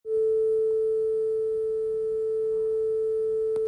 Playing Sine Wave
No artifact